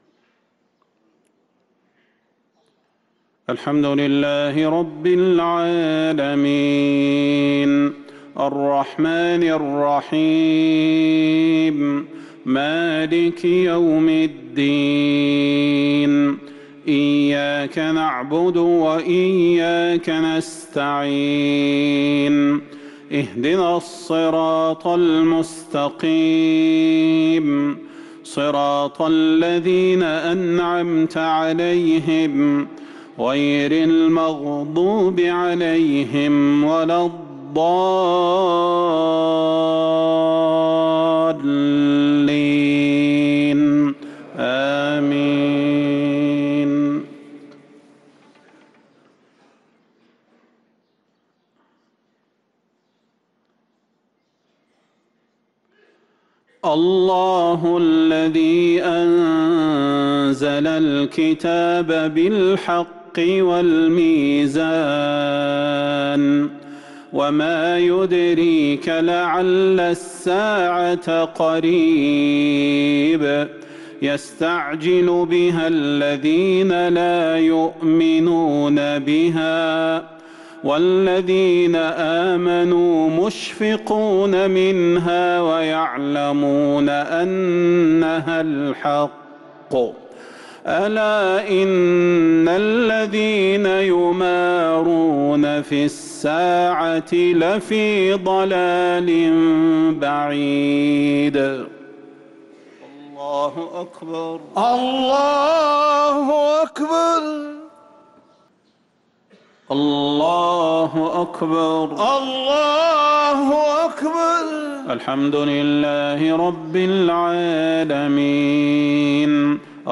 صلاة المغرب للقارئ صلاح البدير 23 رجب 1444 هـ
تِلَاوَات الْحَرَمَيْن .